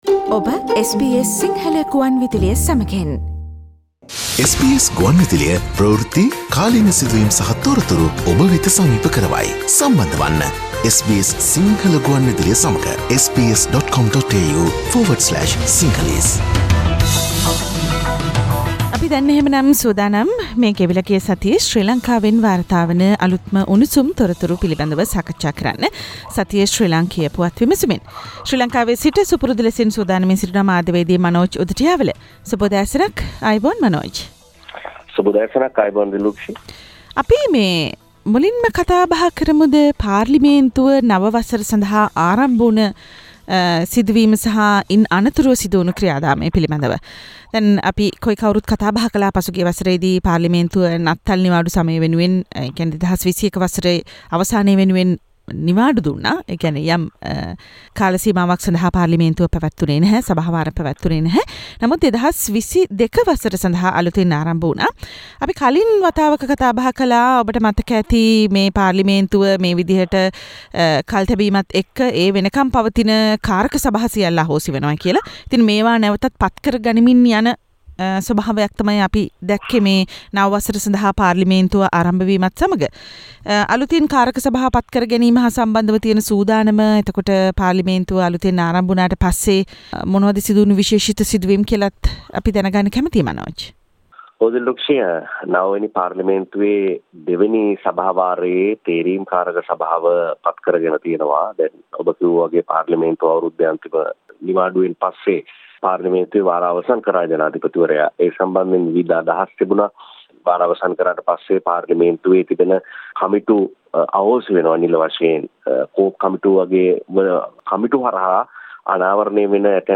පොදුජන එක්සත් පෙරමුණට යලි පන දෙමින් චන්ද්‍රිකාගේ අටුව කඩා පුටුව හැදීමේ උත්සහයක්: ශ්‍රී ලංකා පුවත් විමසුම